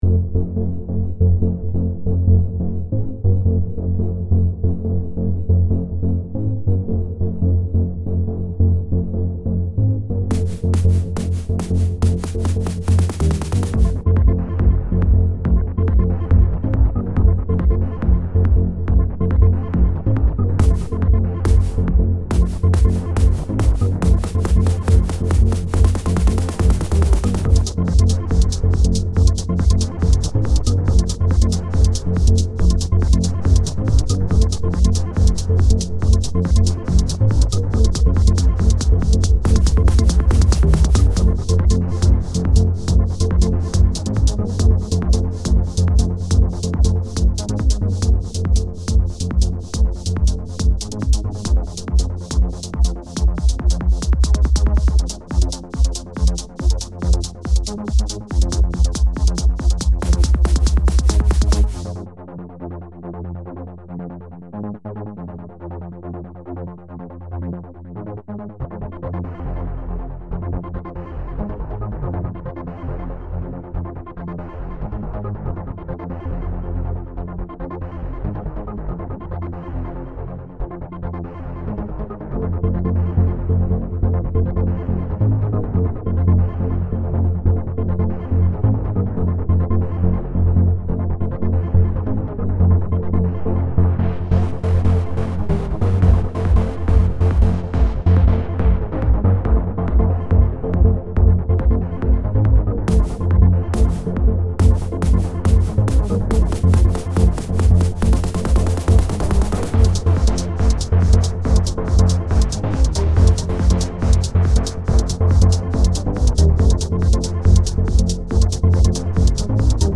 Electronic Music
White Hole 03:18 The first complete piece I made with MULAB. I later ditched the VSTi used (for the non-drum sounds) in this track, as I got the weird sensation that it produced inaudible sounds that made my ears hurt.